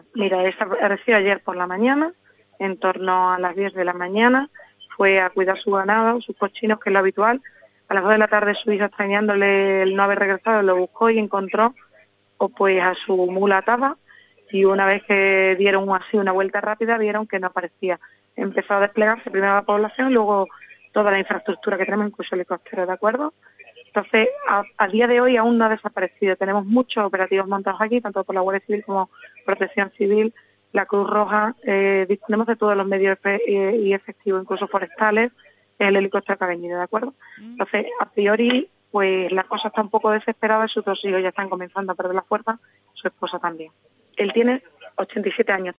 Eva Lozano, alcaldesa de Nogales nos ha explicado la situación